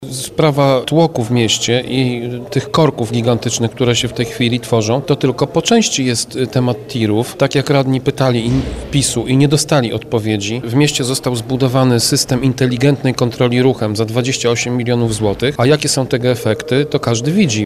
– Popieramy to stanowisko, jednak zakaz nie zmniejszy korków w mieście – mówi przewodniczący klubu radnych Prawa i Sprawiedliwości, Tomasz Pitucha.